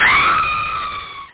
scream.mp3